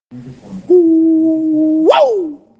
uuuuuuuuuuuuuuu Meme Sound Effect
uuuuuuuuuuuuuuu.mp3